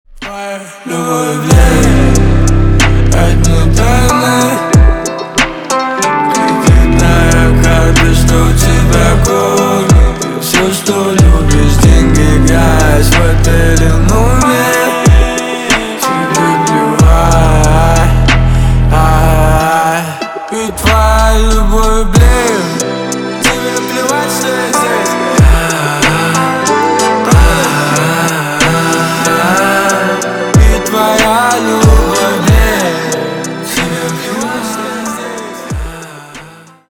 Рэп и Хип Хоп
грустные # спокойные